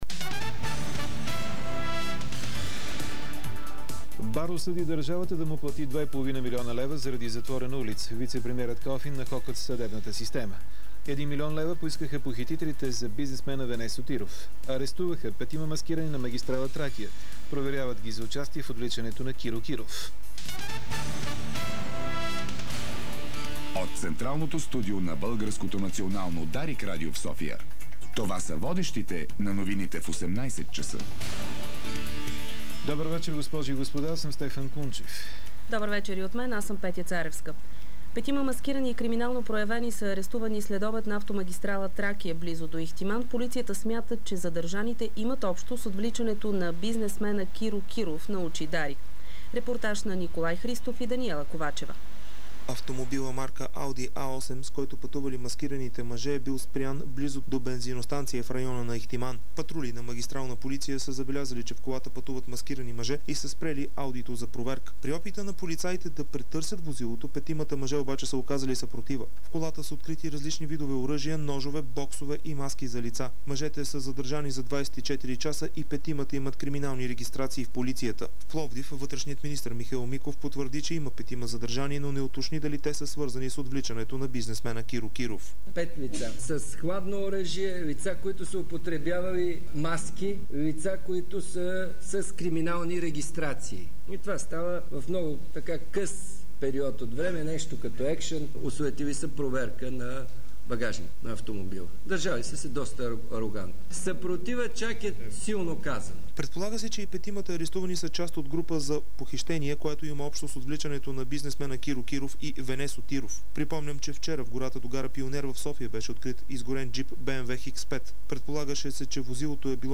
Обзорна информационна емисия - 22.04.2009